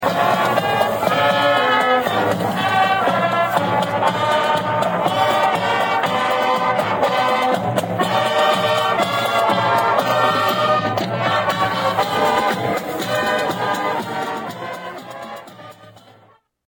3982-parade-2.mp3